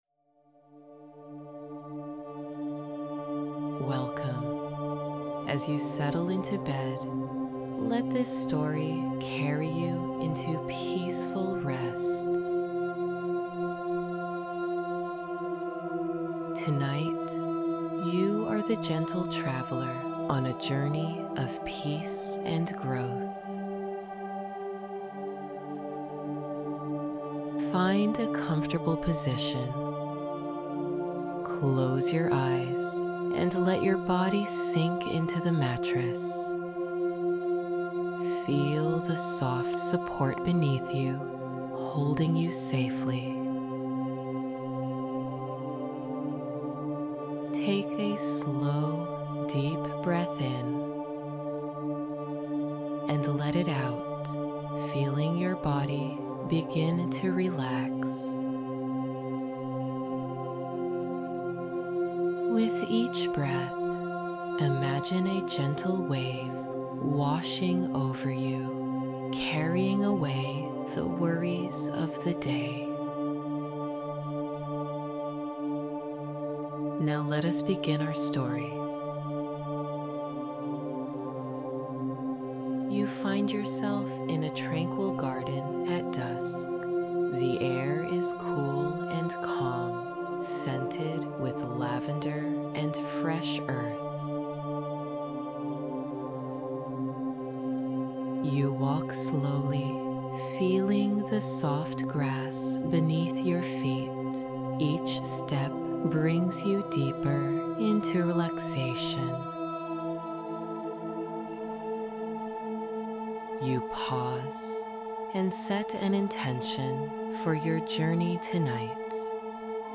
Each day, you’ll get: A short guided meditation (5–10 minutes) Simple yoga stretches for beginners Acupuncture ball exercises for relaxation How to Join: Sign up via email or comment below.